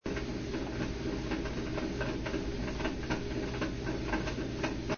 Aufgenommen aus 90 cm Entfernung mit max. Microphone-Empfindlichkeit.
Das Wasch-Geräusch der
KMAL RCM und der GrooVe-Laundry - gleiche Entfernung und gleiche Microphone-Empfindlichkeit.
Von der KM vorwiegend das Rumpeln des LENCO-Reibrad-Antriebes, bei beiden das Geräusch der Bürste auf der mit 80 rpm rotierenden Platte.